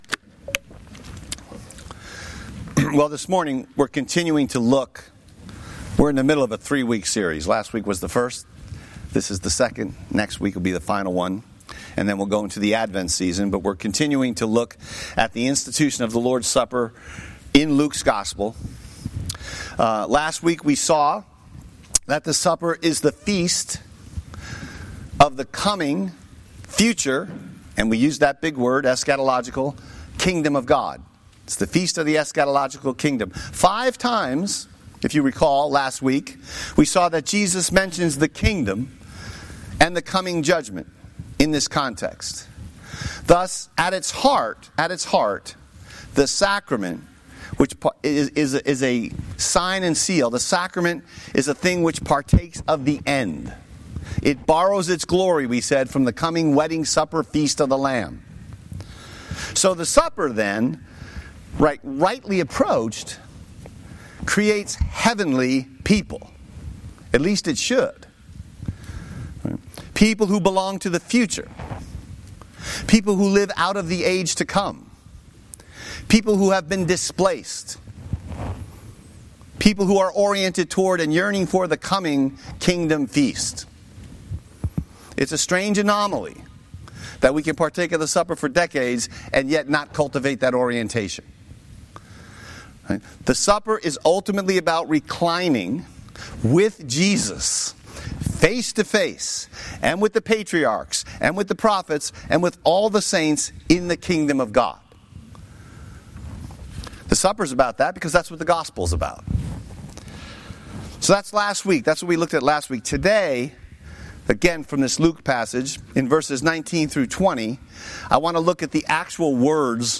Liquid, Edible Gospel Sermon text: Luke 22:14-30